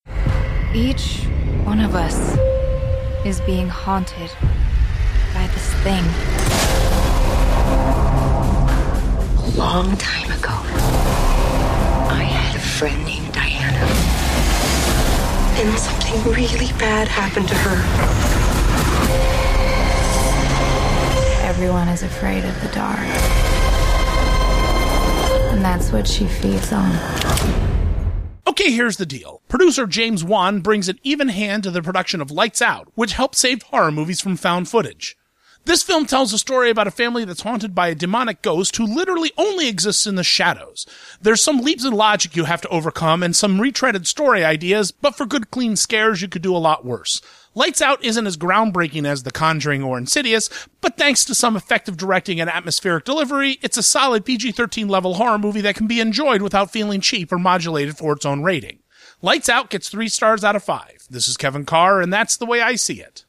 ‘Lights Out’ Radio Review